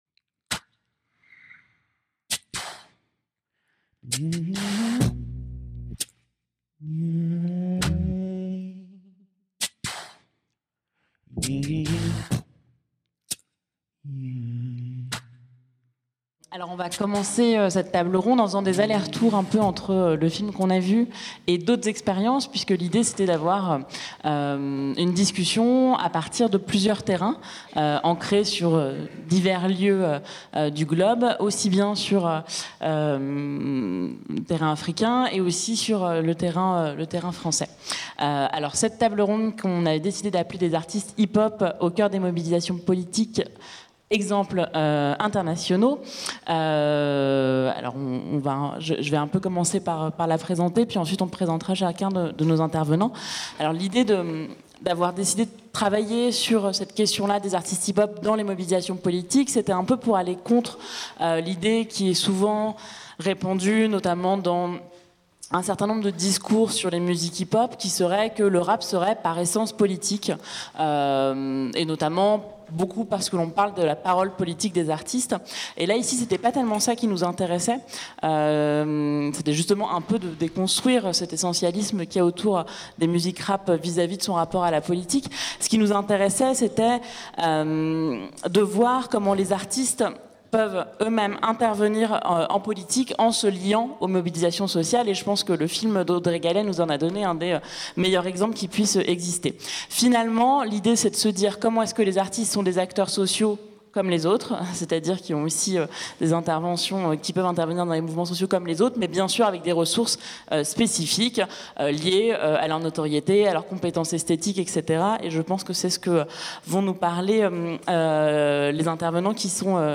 Table Ronde Des artistes hip-hop au cœur des mobilisations politiques : exemples internationaux (1) | Canal U